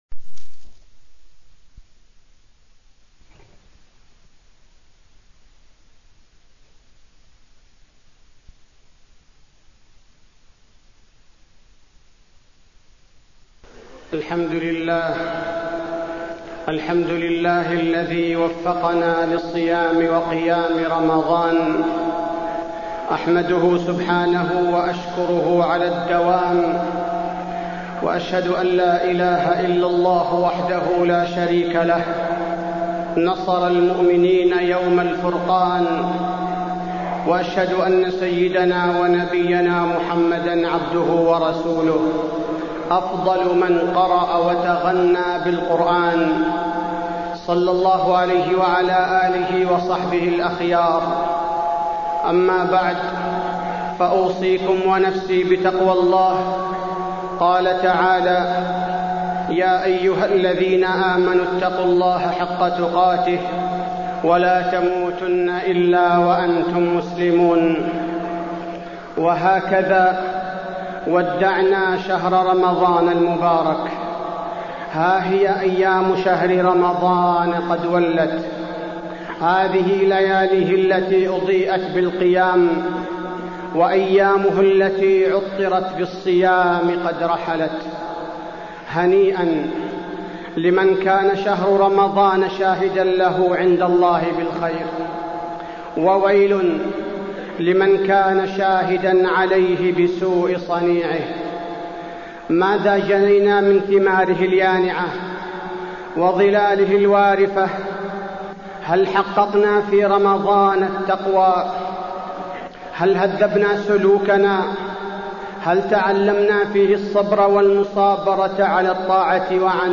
تاريخ النشر ٣٠ رمضان ١٤٢٨ هـ المكان: المسجد النبوي الشيخ: فضيلة الشيخ عبدالباري الثبيتي فضيلة الشيخ عبدالباري الثبيتي ما بعد رمضان